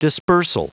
Prononciation du mot dispersal en anglais (fichier audio)
Prononciation du mot : dispersal